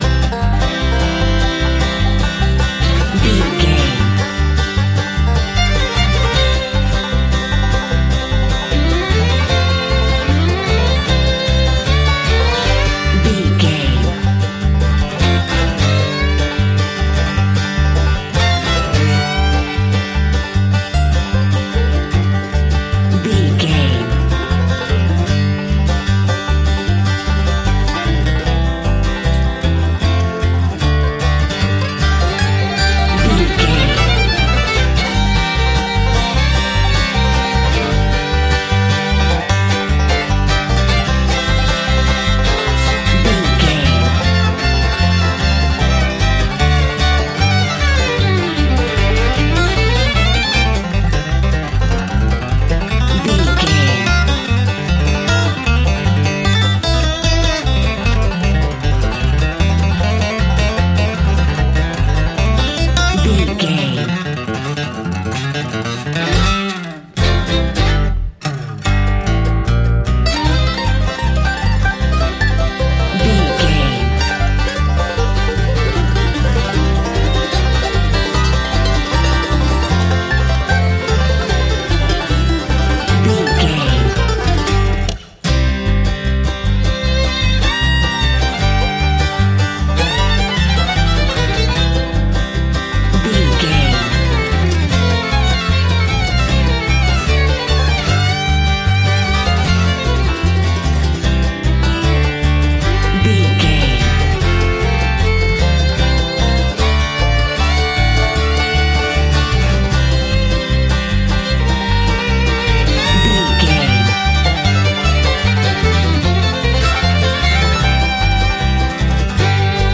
Ionian/Major
banjo
violin
double bass
acoustic guitar
bluegrass
joyful